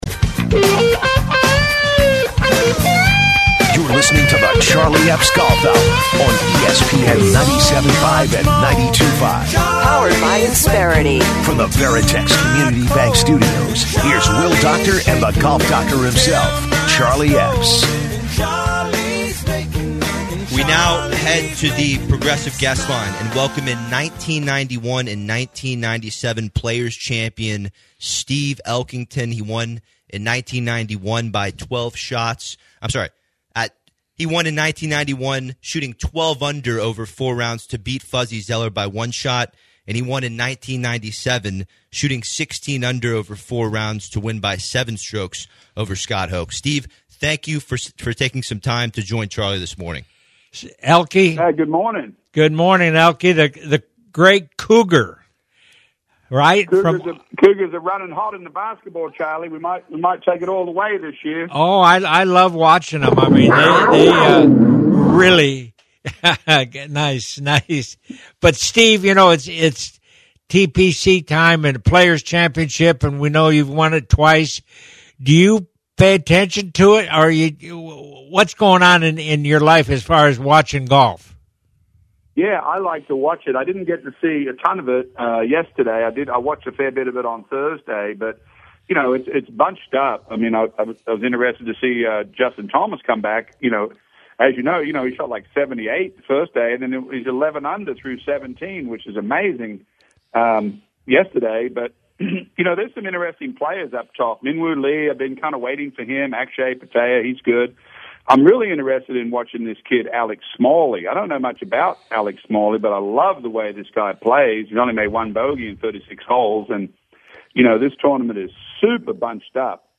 3/15/2025 The Steve Elkington Interview
Two-Time PLAYERS champion Steve Elkington joins show!